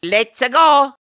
One of Luigi's voice clips in Mario Kart Wii